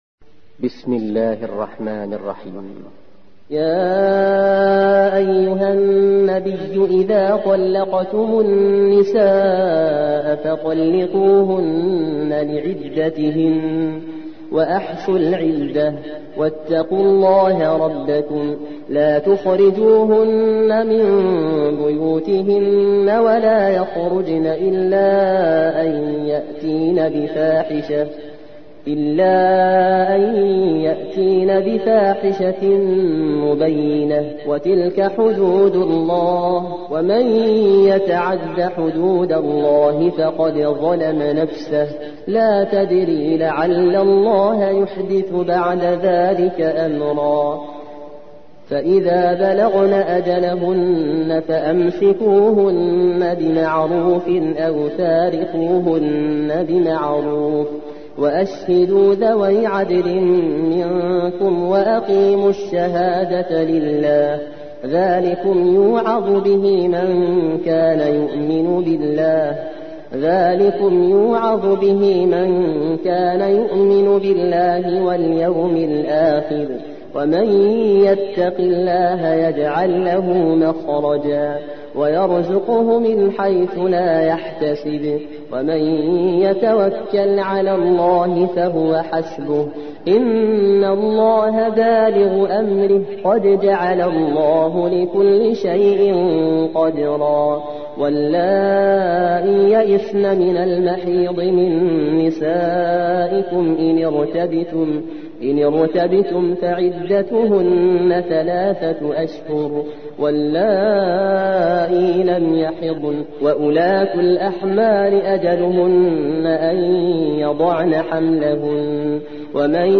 سورة الطلاق / القارئ